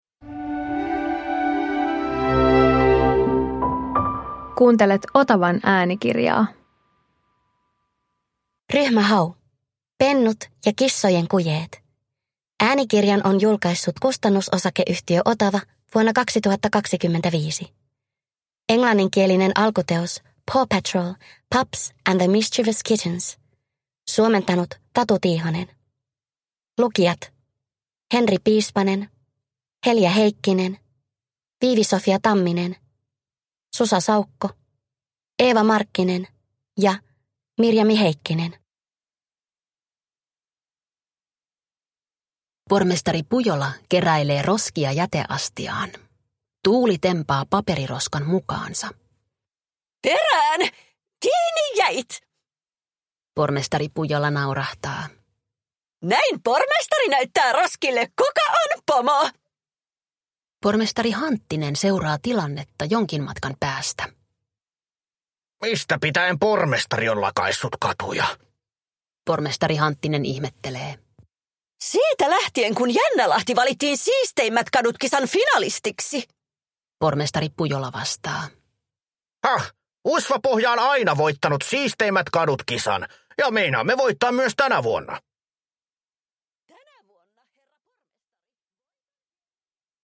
Ryhmä Hau - Pennut ja kissojen kujeet – Ljudbok